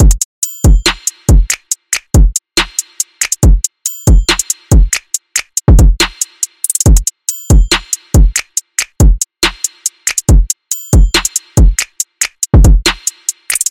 狼哨
标签： 经典 关闭 哨子
声道立体声